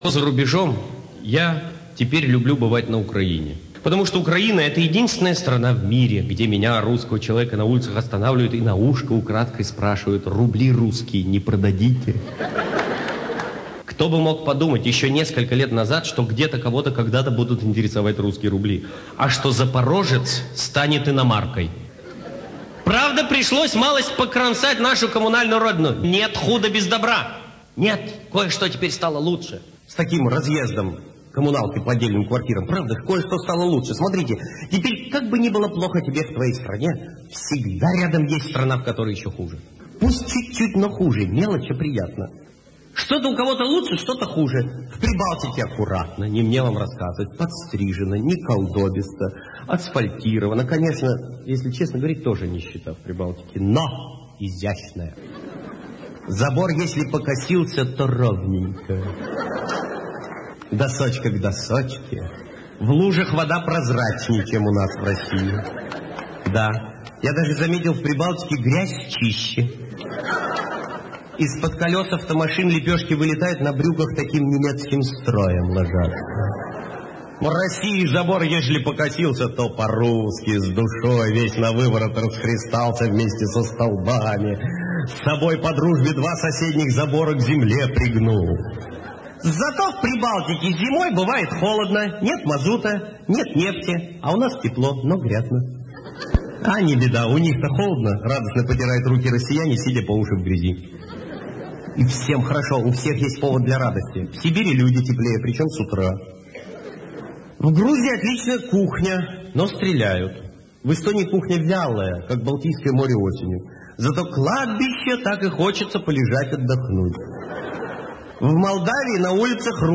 Юмор.